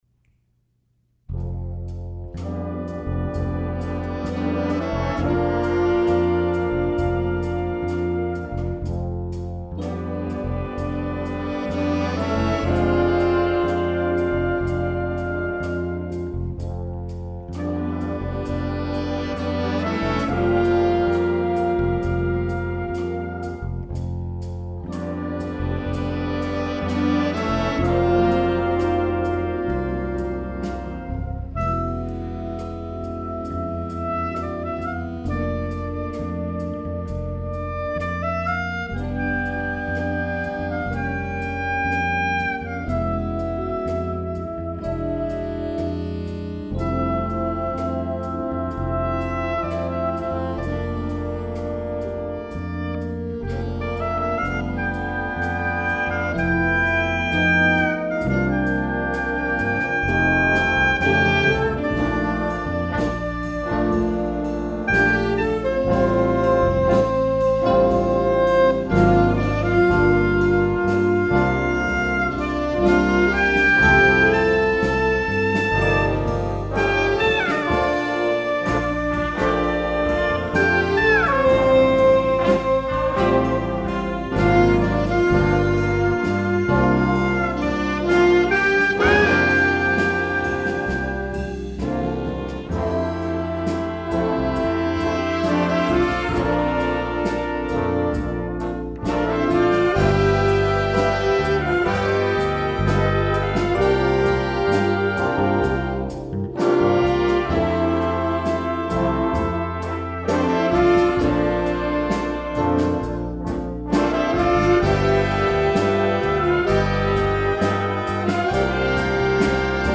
a gorgeous rock ballad feature